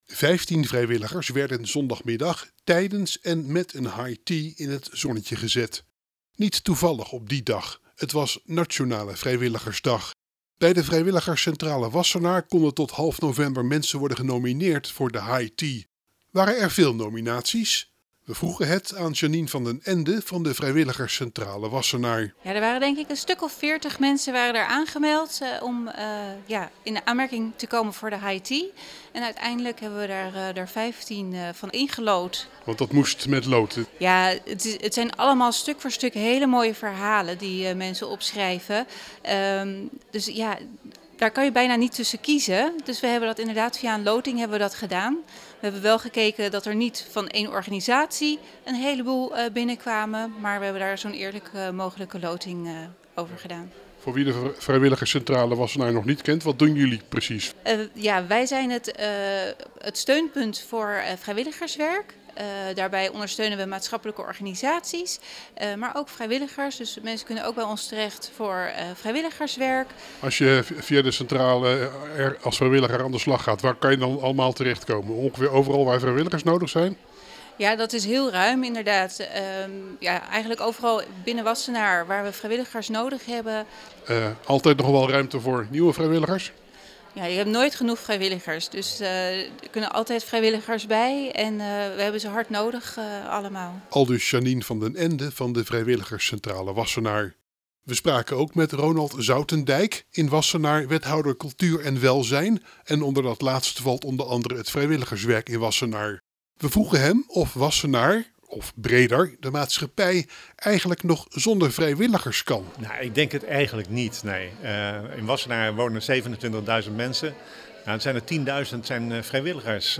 High-Tea-voor-Vrijwiligers-in-Wassenaar.mp3